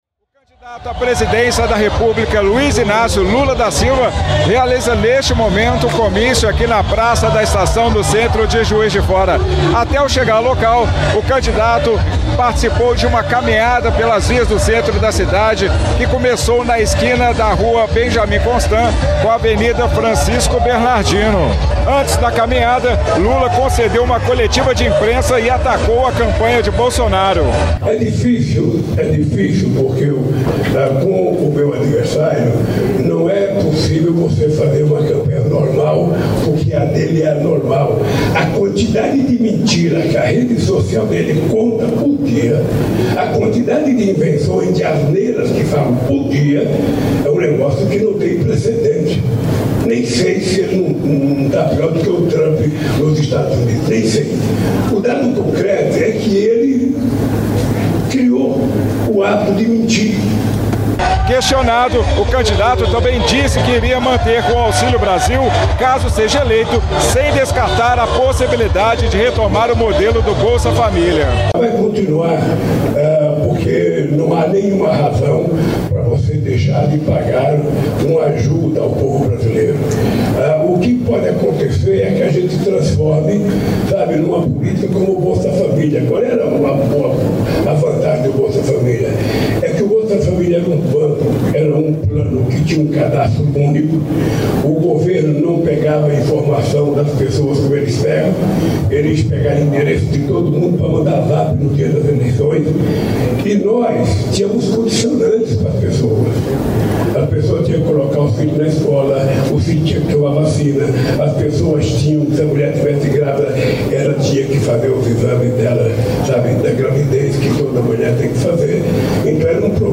Lula diz acreditar na vitória em Minas Gerais durante comício nesta , sexta-feira, 21, em Juiz de Fora.
lula-comicio-em-minas-gerais-juiz-de-fora.mp3